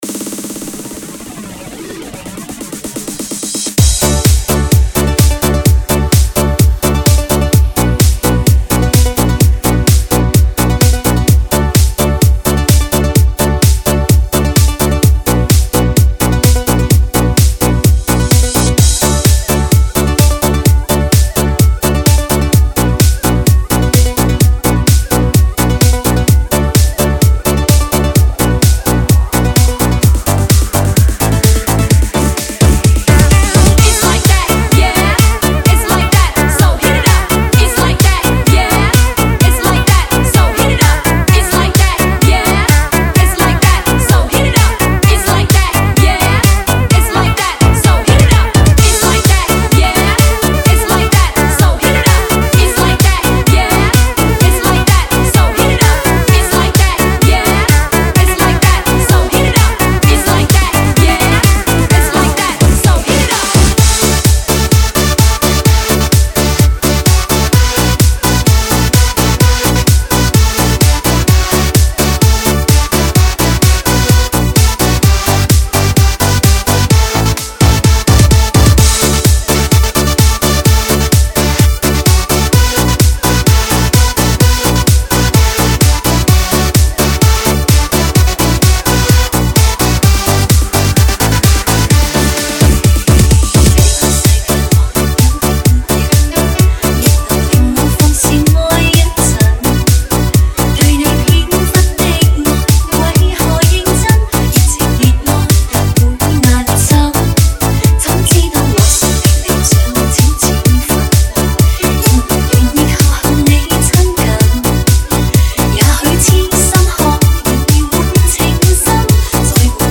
感受激情动感嗨曲 体验旅途有你 (DJ)不寂寞！HOLD住激情 女声篇
绝对震撼经典迪厅嗨曲 专业DJ大师精心灌录 打造迪高嗨王至尊舞曲
最热辣的音乐节拍 全城热卖
三维高临场音效 采用德国1:1母带直刻技术